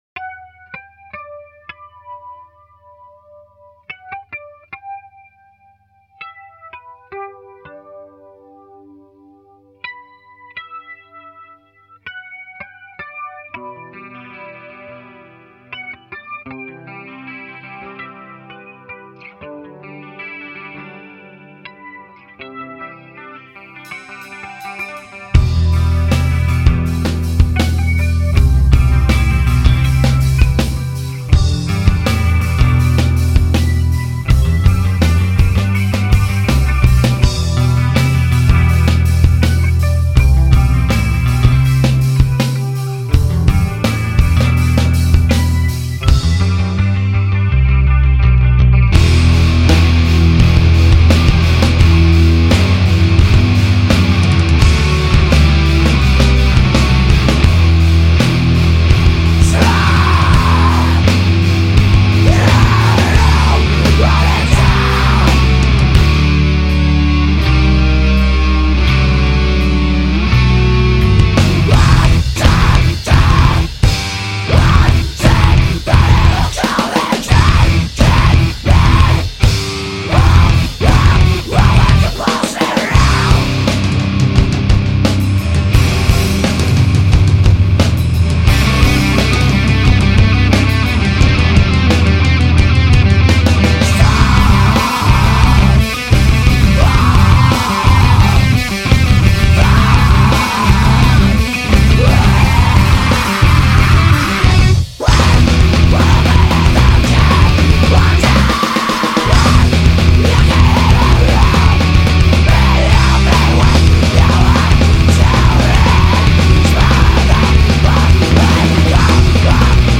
Screamo